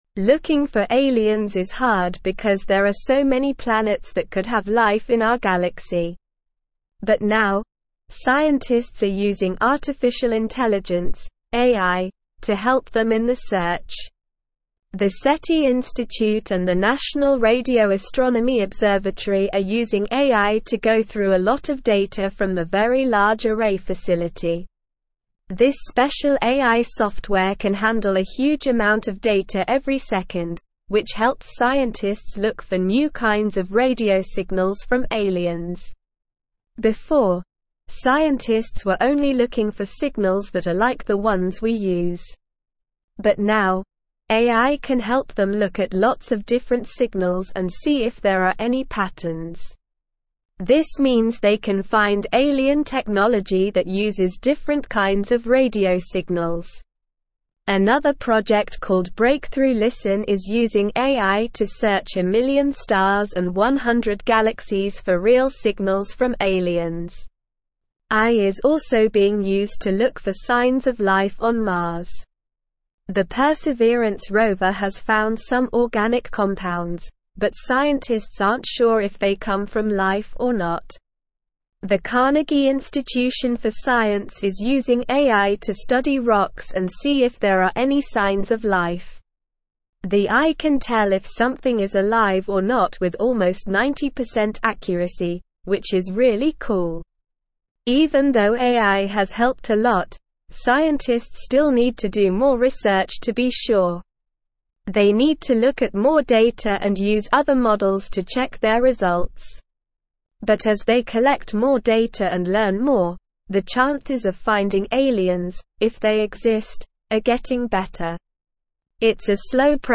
Slow
English-Newsroom-Upper-Intermediate-SLOW-Reading-AI-Revolutionizes-Search-for-Extraterrestrial-Life.mp3